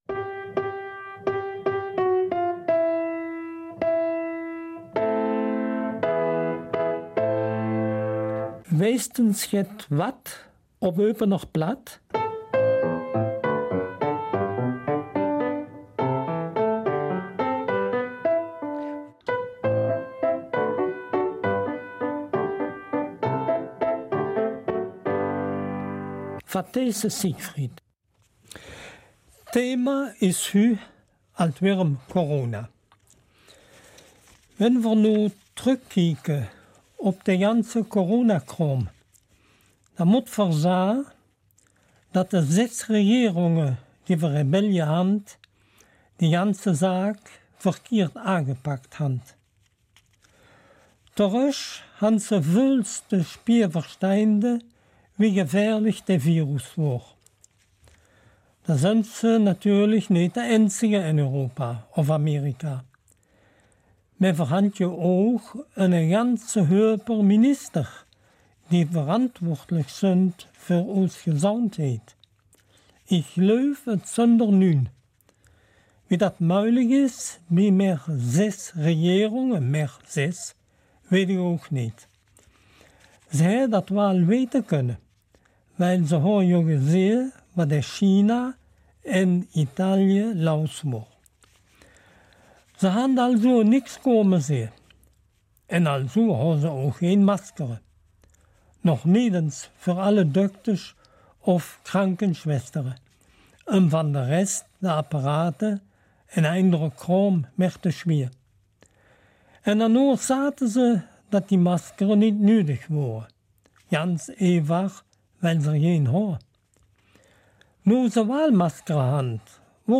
Eupener Mundart - 14. Juni